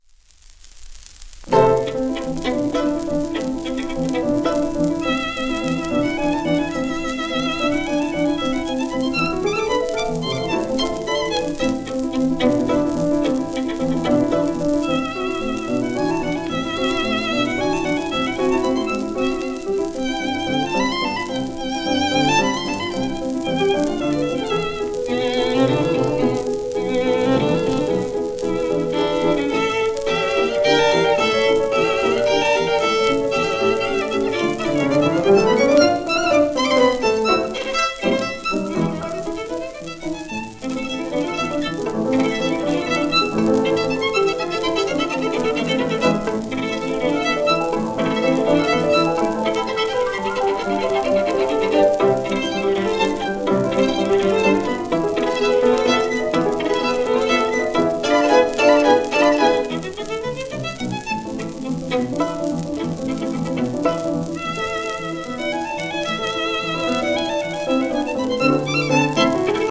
w/ピアノ
1946年頃の録音